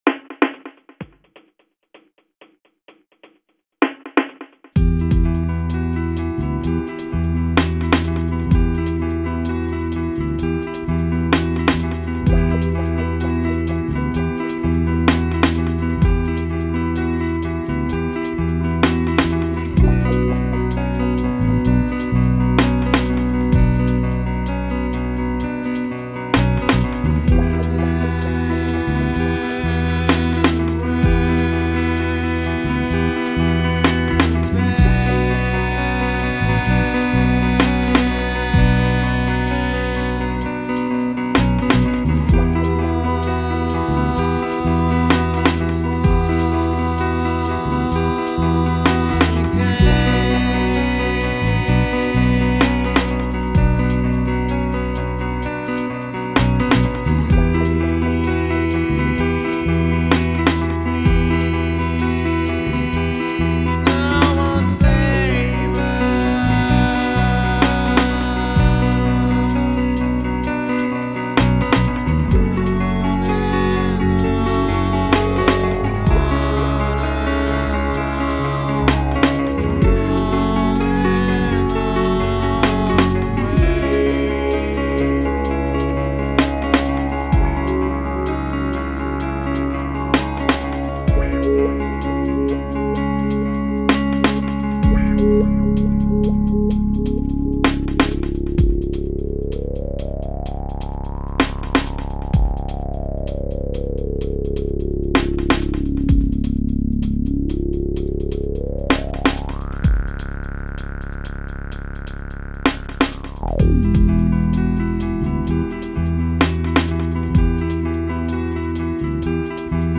singing and guitar
bass guitar and recording